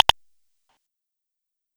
testClick.wav